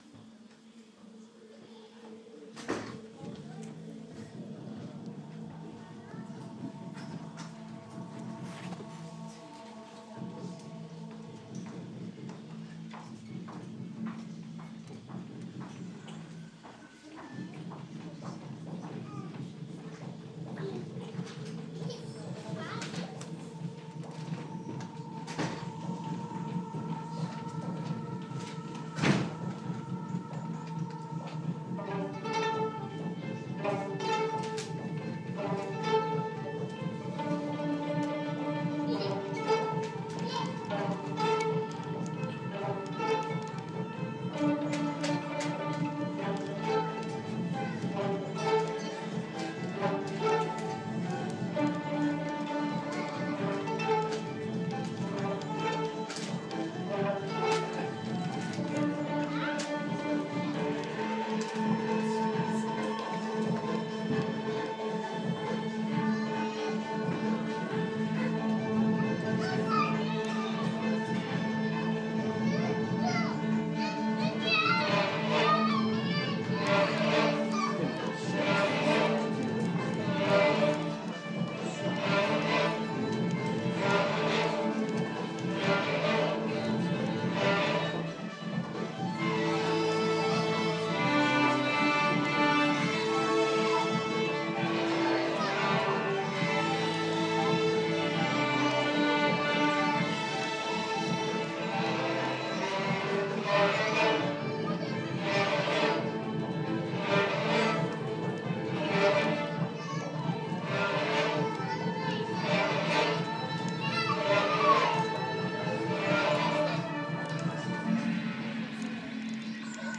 Y4 violins